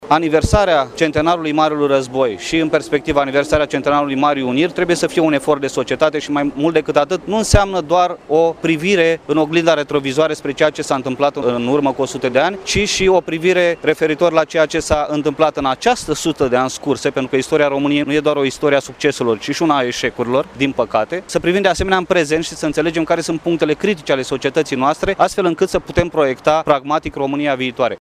Astăzi, în prezenţa oficialităţilor locale, la Iaşi, au fost dezvelite două plăci care marchează importanţa şi rolul pe care le-a avut orașul în Primul Război Mondial.